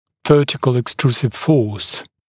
[‘vɜːtɪkl ɪk’struːsɪv fɔːs][‘вё:тикл ик’стру:сив фо:с]вертикальная экструзионная сила